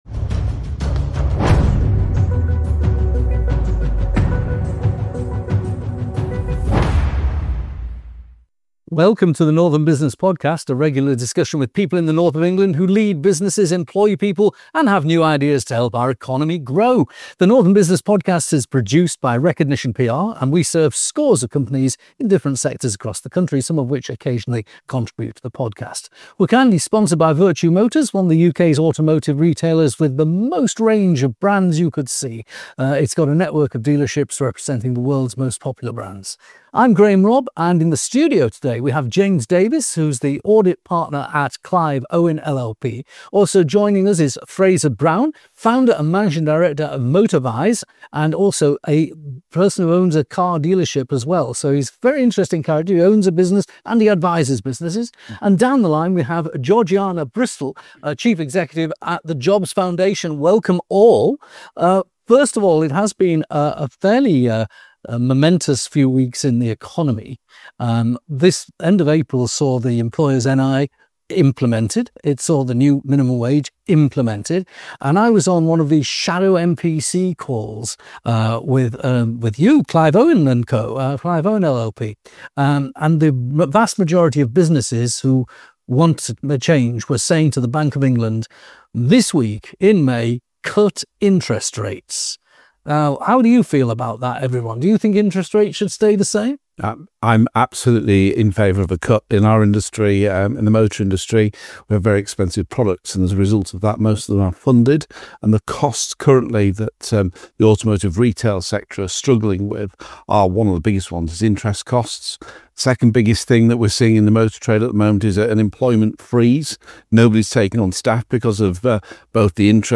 Welcome to the Northern Business Podcast, a regular discussion with people in the North of England who lead businesses, employ people and have new ideas to help our economy grow.